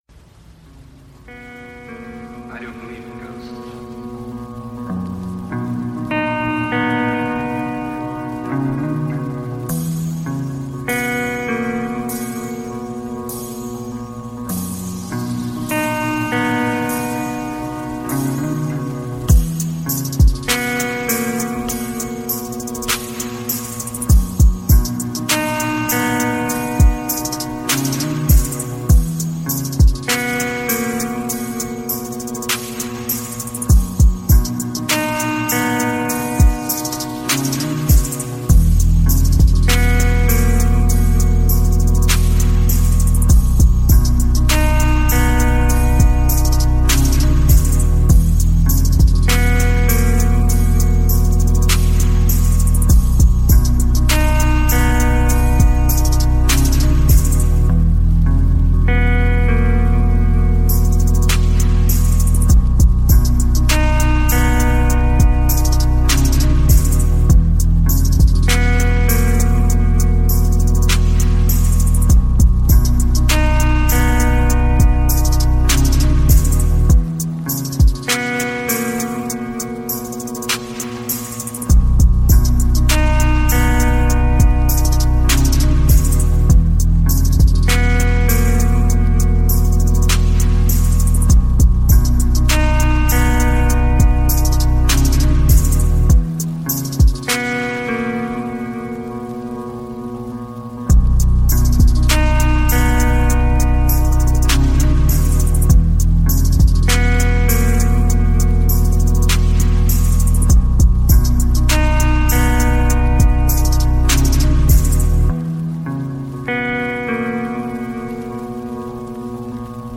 Pluie Calme : Nuit Paisible